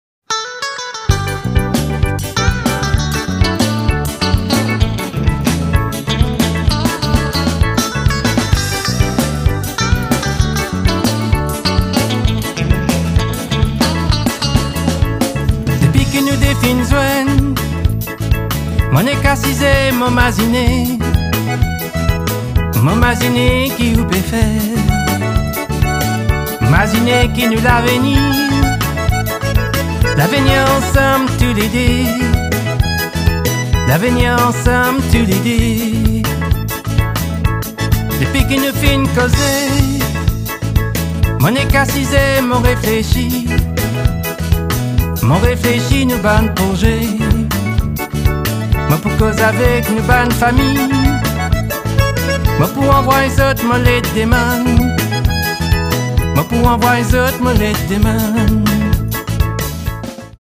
sax, guitars, bass, contrabass, clavier, sitar and violin